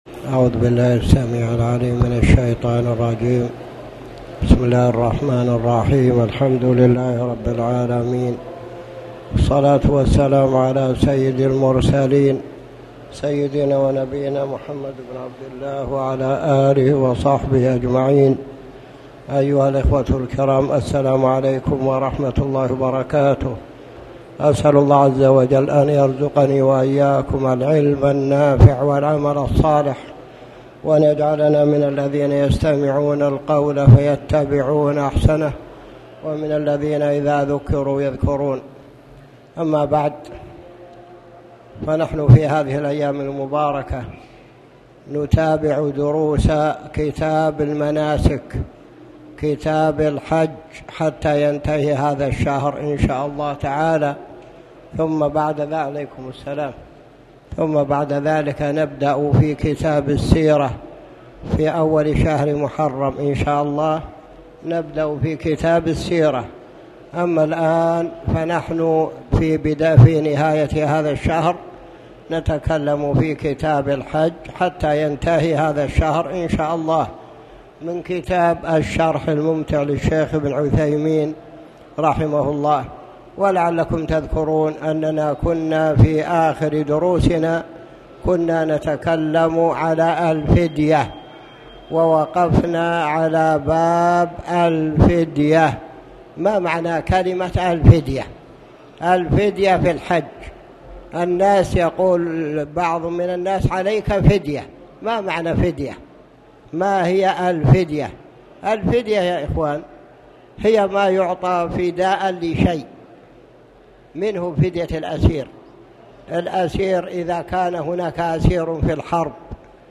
تاريخ النشر ١٨ ذو الحجة ١٤٣٨ هـ المكان: المسجد الحرام الشيخ